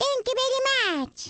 One of Baby Mario's voice clips from the Awards Ceremony in Mario Kart: Double Dash!!